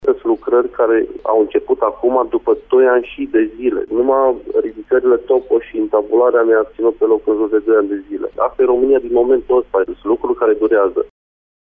Primarul comunei Bozovici spune că o mare vină în amenajarea zonei o au şi autorităţile care eliberează autorizaţiile necesare şi care tărăgănează defapt acest lucru de la un la altul: